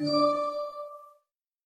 Whisper.wav